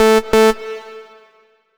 Session 04 - NRG Lead.wav